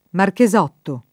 Marchesotto [ marke @0 tto ] cogn.